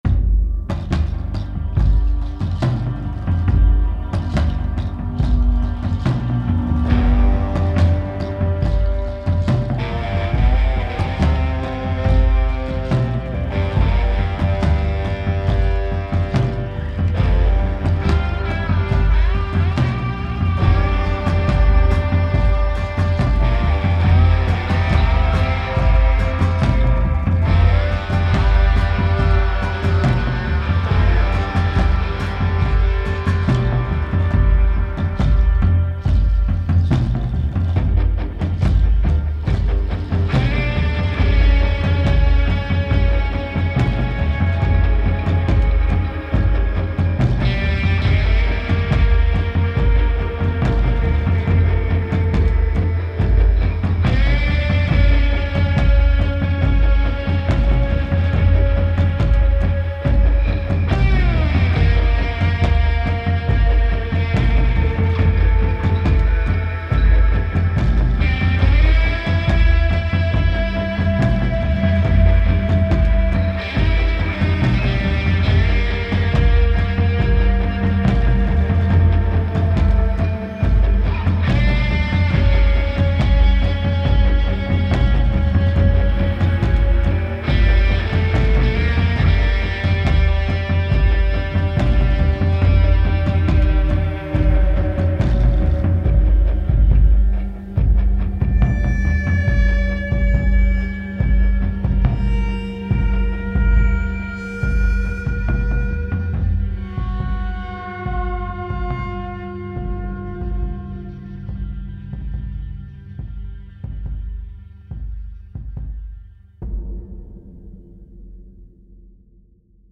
backwoods blues, creepy, thematic